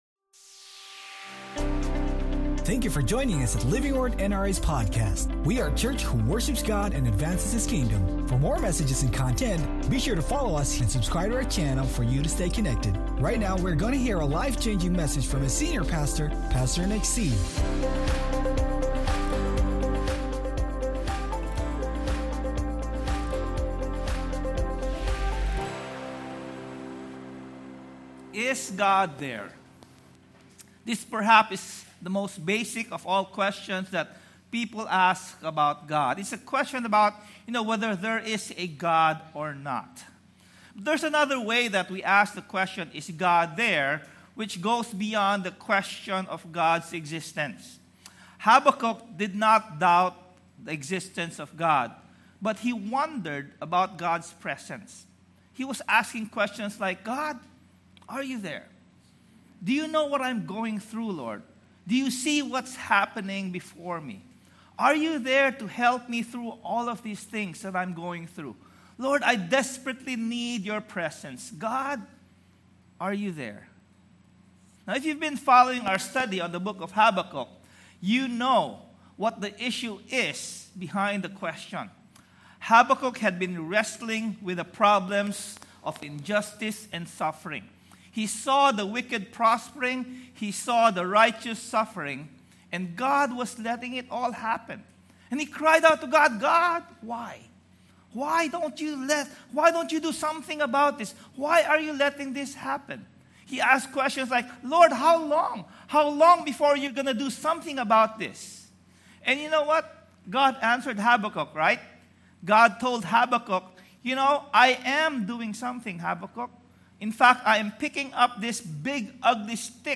When we choose to remember what God has done, it frames our perspective as we wait for what he will do. Sermon Title: WHEN WE SEE GOD Sermon Text